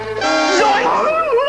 zoinks.wav - Scoob and Shaggy get scared!